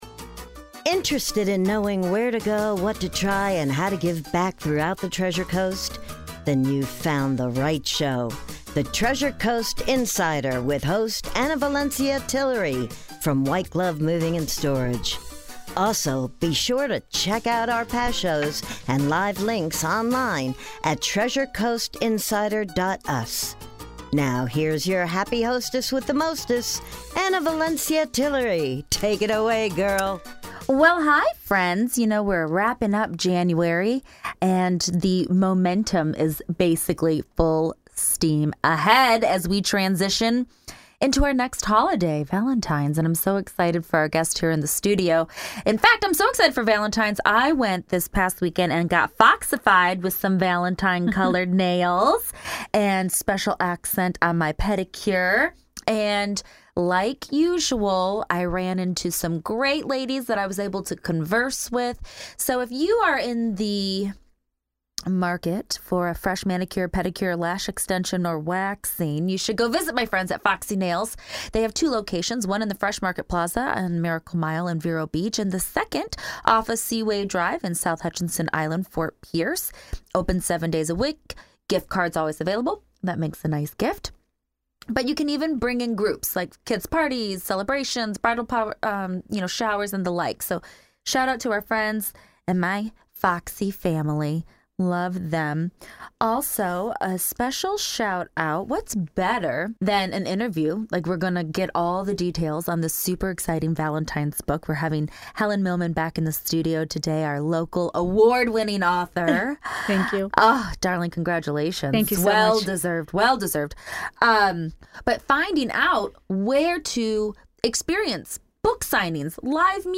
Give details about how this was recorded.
As always live link to support our sponsor and tune in on 101.7fm Saturdays.